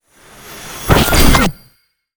magic_conjure_charge1_01.wav